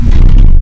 smash.ogg